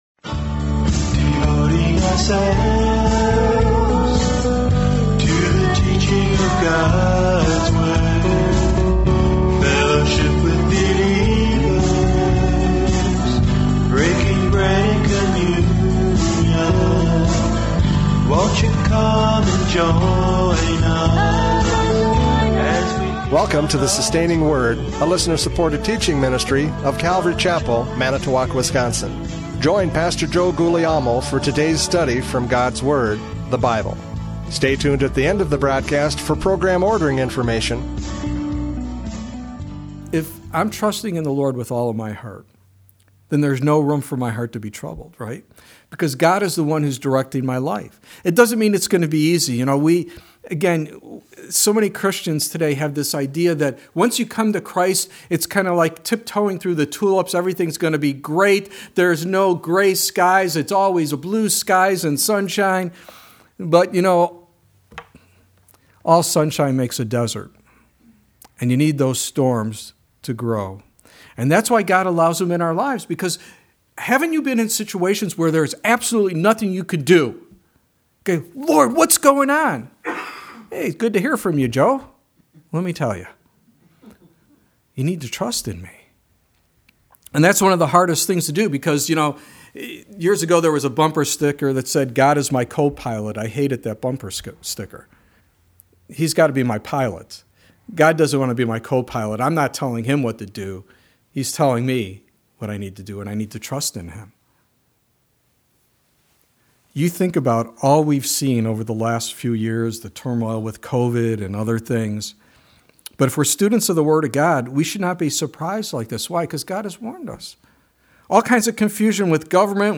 John 14:1-4 Service Type: Radio Programs « John 14:1-4 The Comfort of Christ!